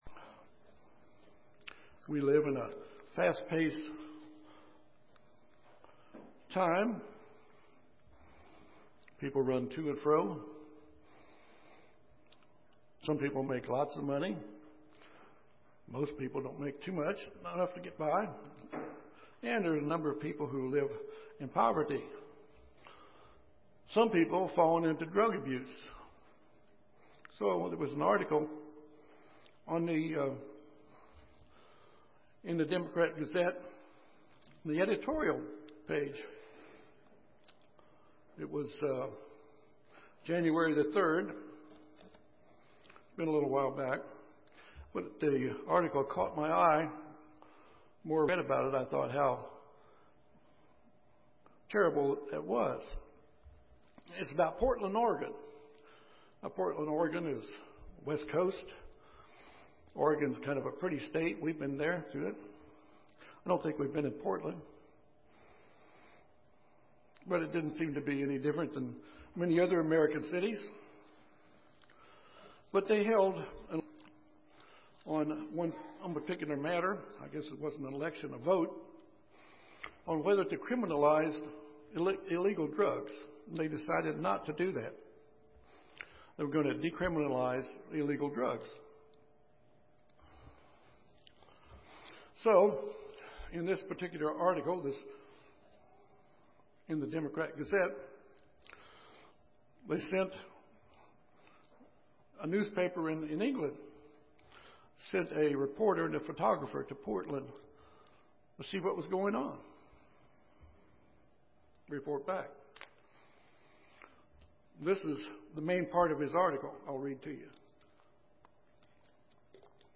Today many seek to satisfy their desires without concern of the effects that seeking gratification has upon their lives. The purpose of this sermon is to encourage us to live the kind of life that God wants us to have.